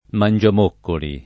[ man J am 1 kkoli o man J am 0 kkoli ]